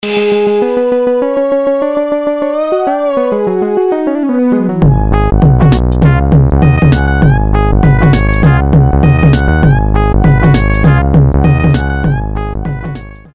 Nokia полифония. Зарубежные